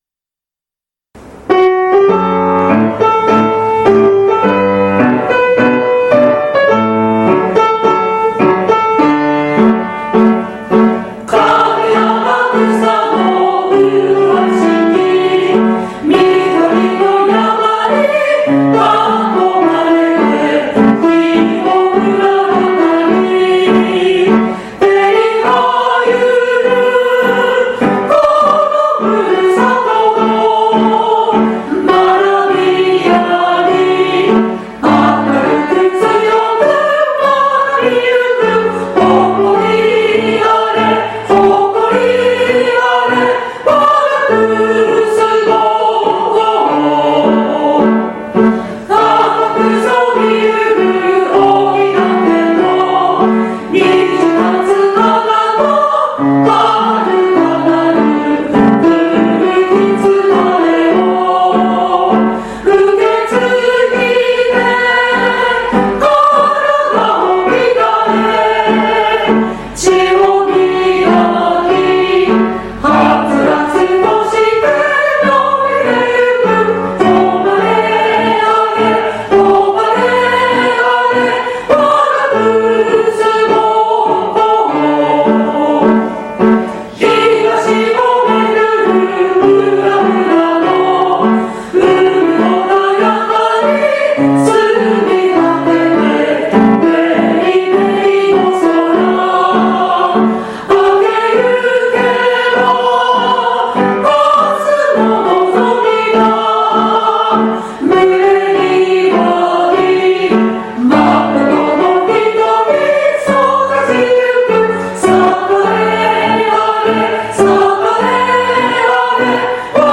現行政区  旧行政区 No 小学校名  校歌楽譜・歌詞・概要  校歌音源（歌・伴奏） 　　備考
kusubosho_kouka_gattsho.mp3